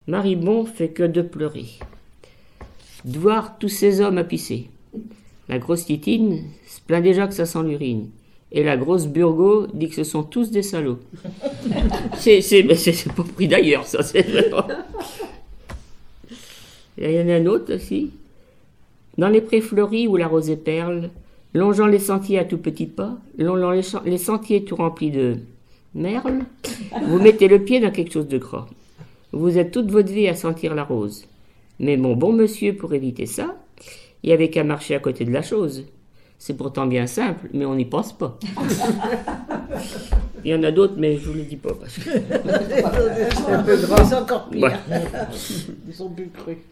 Genre fable
collectif de chanteuses de chansons traditionnelles
Catégorie Récit